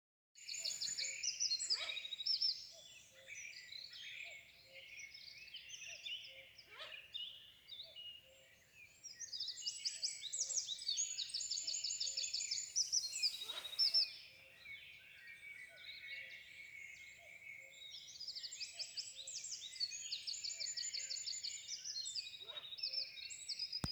длиннохвостая неясыть, Strix uralensis
Примечания/T izprovocēts pa dienu, M pirmoreiz atbild aptuveni šeit.